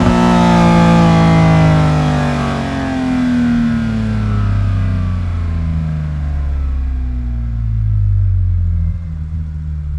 rr3-assets/files/.depot/audio/Vehicles/i6_01/i6_01_Decel.wav